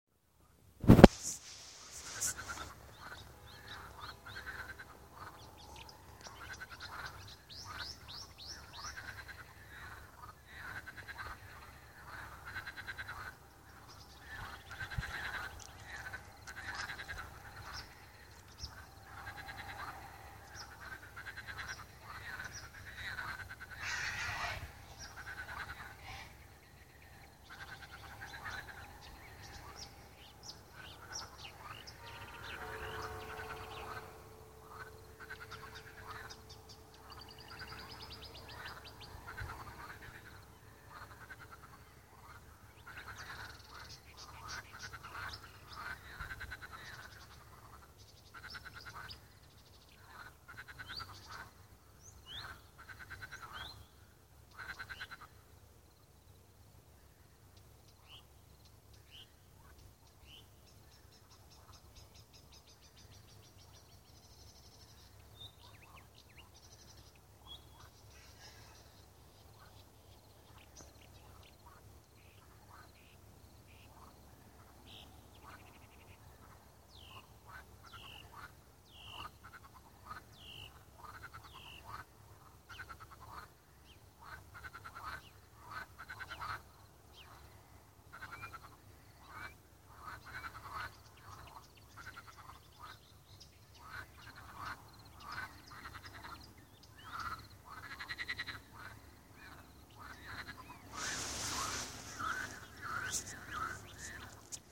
Nachtigall gehört und aufgenommen
Nachtigall-Mai-2014.mp3